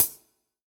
UHH_ElectroHatD_Hit-17.wav